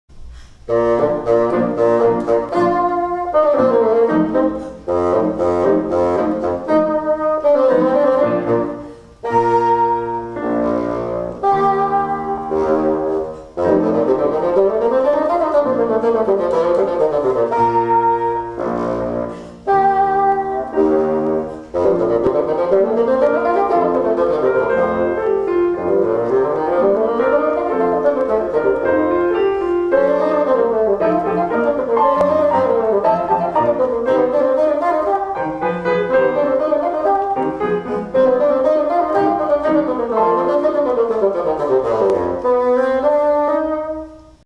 FAGOT (viento madera)